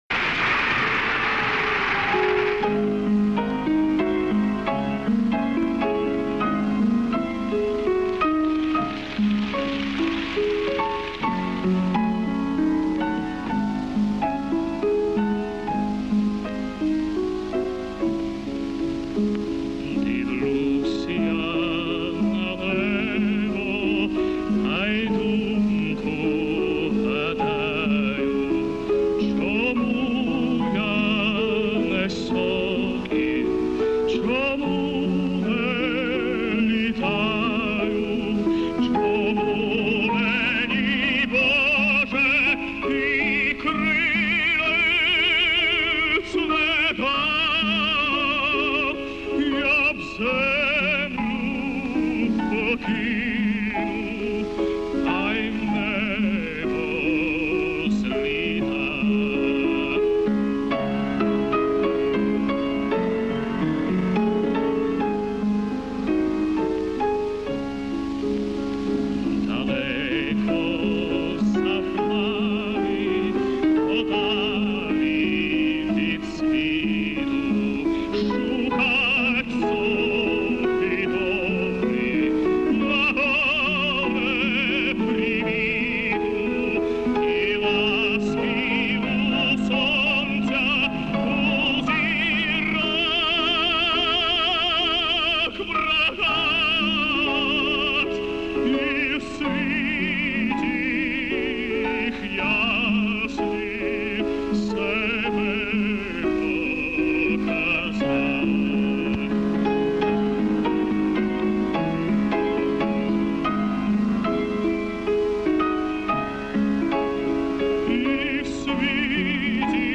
Сольный концерт Муслима Магомаева в Киеве 1964г.